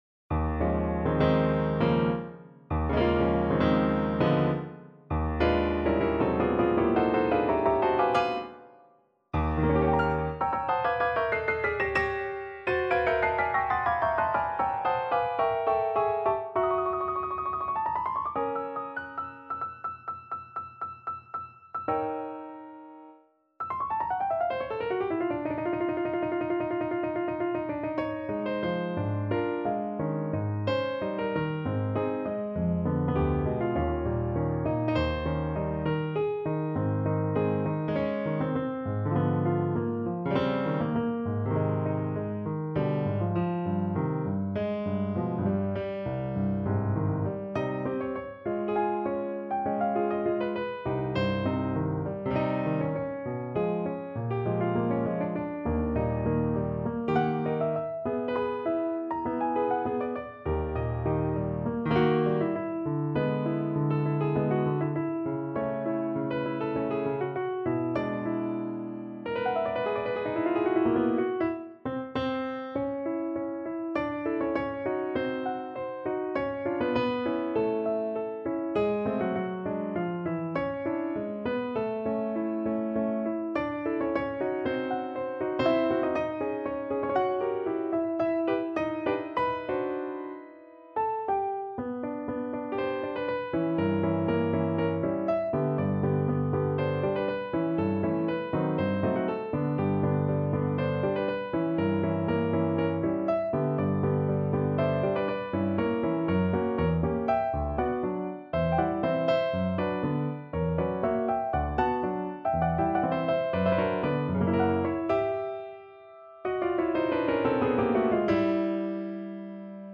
solo piano
Instrument: Piano
Style: Classical